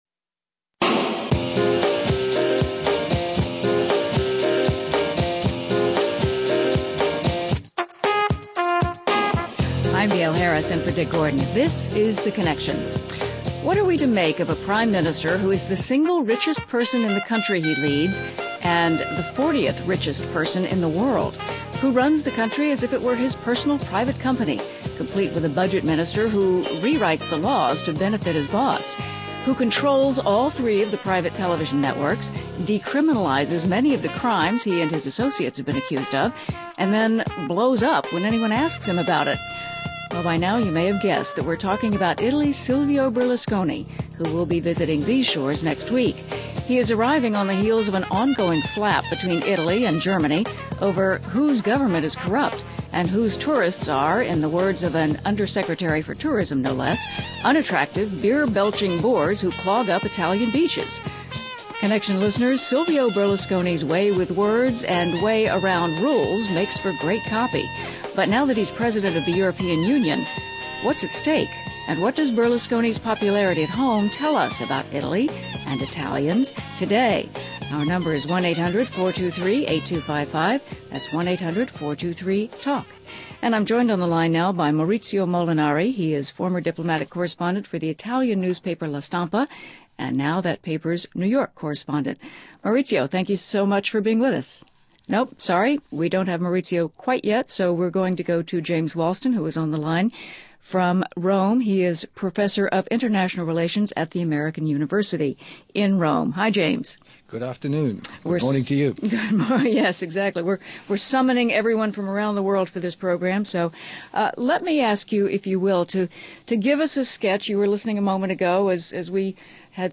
But where some see signs of peace, others believe deep sectarian divides remain, along with wounds that politicians alone cannot heal. A conversation with Paul Murphy, the British Secretary of State for Northern Ireland, on reconciling the past and marching forward.